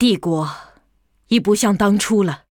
文件 文件历史 文件用途 全域文件用途 Lobato_amb_05.ogg （Ogg Vorbis声音文件，长度2.5秒，103 kbps，文件大小：31 KB） 文件说明 源地址:游戏语音 文件历史 点击某个日期/时间查看对应时刻的文件。 日期/时间 缩略图 大小 用户 备注 当前 2018年11月17日 (六) 03:34 2.5秒 （31 KB） 地下城与勇士  （ 留言 | 贡献 ） 分类:洛巴赫 分类:地下城与勇士 源地址:游戏语音 您不可以覆盖此文件。